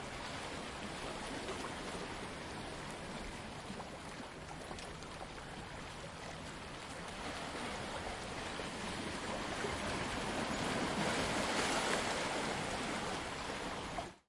大不列颠 " 平静的海浪 STE024
描述：平静的波浪和一点风。我自己用ZOOM H4。
标签： 沙滩 大海 海浪 海边 沙滩 海水 奇石 海滨 海岸 性质 现场录音 音景
声道立体声